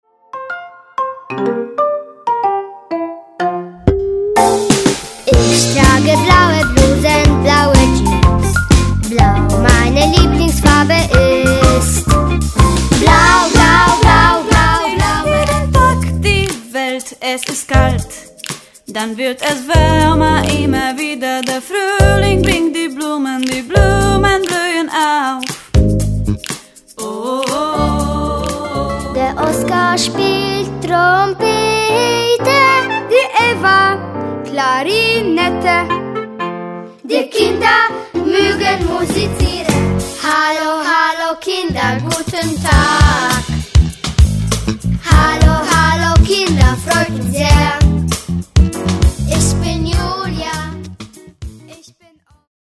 piosenek w wykonaniu dziecięcym